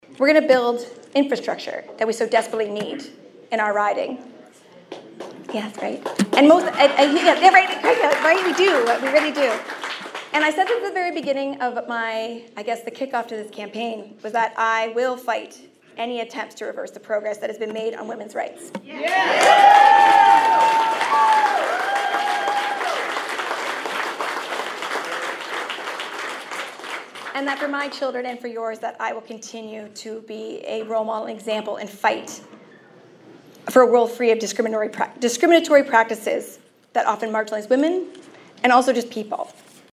Harrison held her election party at the Canadian Canoe Museum on Monday.